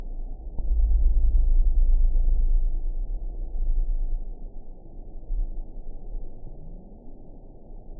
event 922718 date 03/20/25 time 23:23:06 GMT (1 month, 1 week ago) score 7.61 location TSS-AB10 detected by nrw target species NRW annotations +NRW Spectrogram: Frequency (kHz) vs. Time (s) audio not available .wav